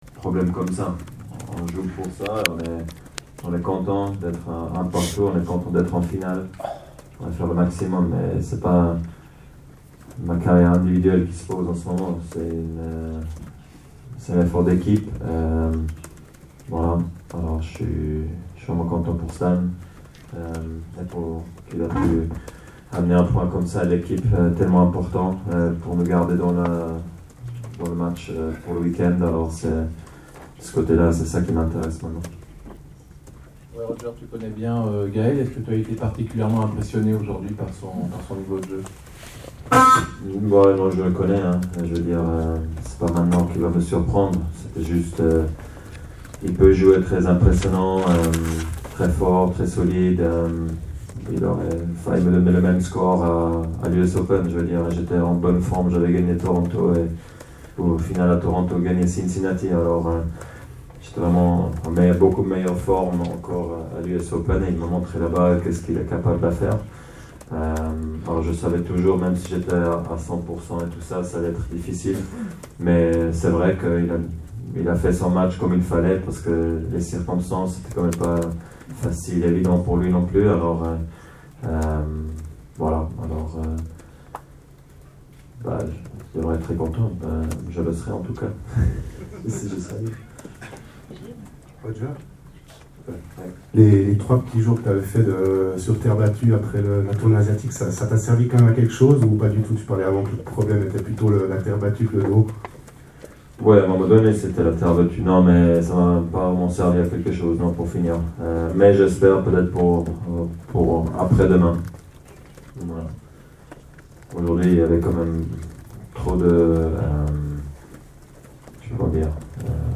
Gli audio del post partita: